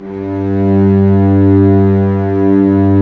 Index of /90_sSampleCDs/Roland LCDP13 String Sections/STR_Vcs FX/STR_Vcs Sordino